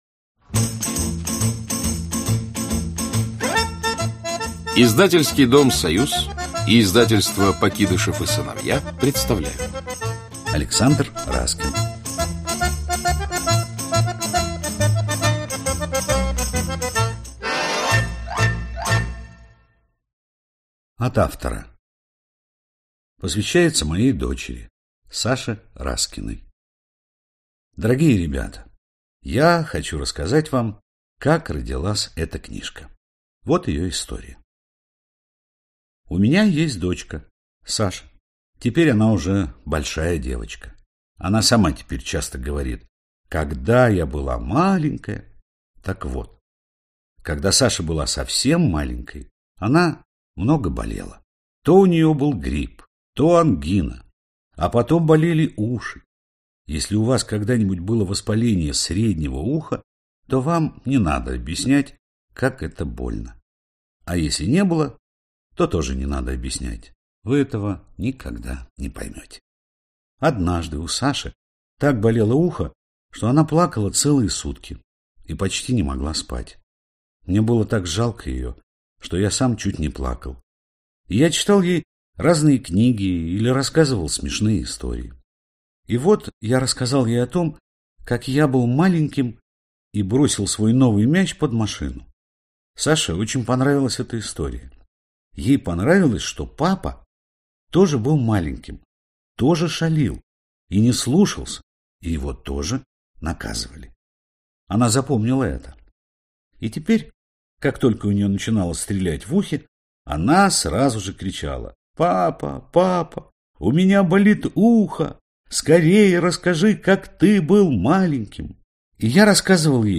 Аудиокнига Как папа был маленьким | Библиотека аудиокниг
Aудиокнига Как папа был маленьким Автор Александр Борисович Раскин Читает аудиокнигу Юрий Стоянов.